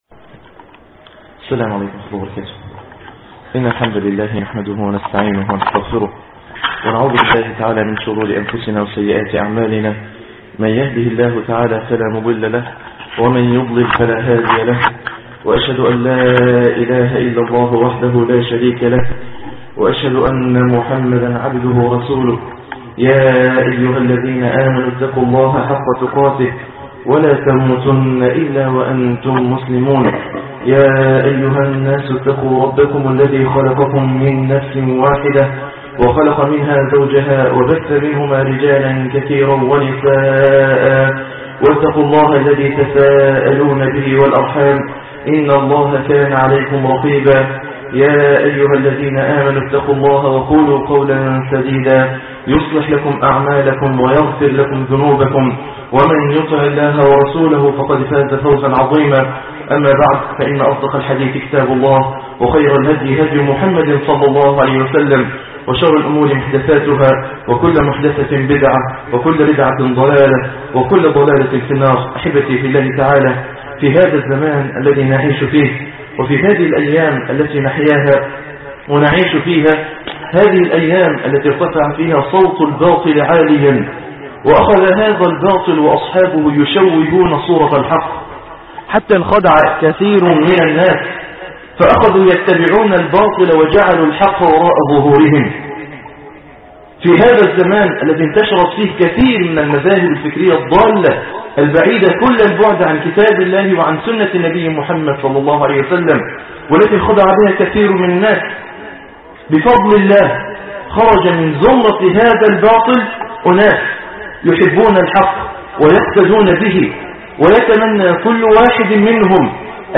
عنوان المادة خطبة عيد الأضحى 1427هـ تاريخ التحميل الأربعاء 28 نوفمبر 2007 مـ حجم المادة 4.14 ميجا بايت عدد الزيارات 11,164 زيارة عدد مرات الحفظ 1,885 مرة إستماع المادة حفظ المادة اضف تعليقك أرسل لصديق